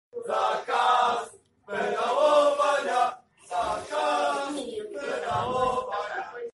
Następnie zaczęli skandować hasło wyrażające ich niechęć do środowisk LGBT